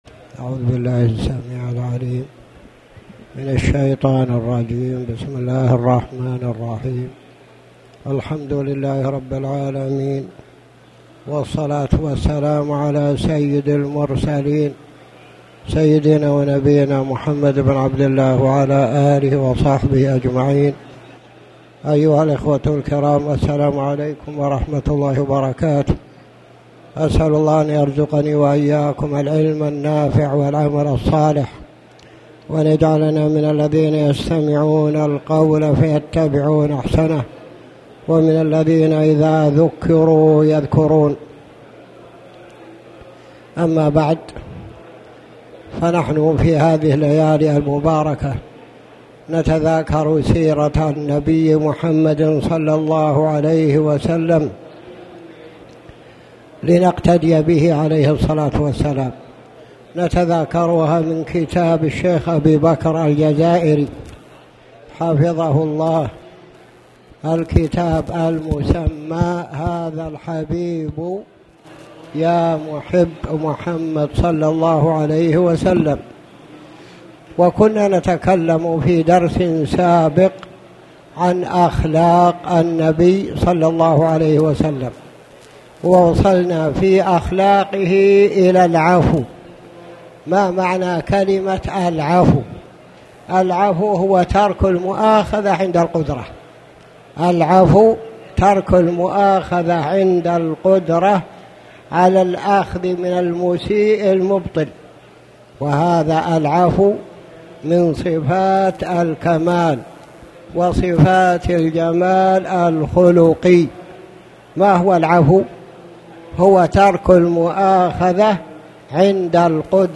تاريخ النشر ١٠ محرم ١٤٣٩ هـ المكان: المسجد الحرام الشيخ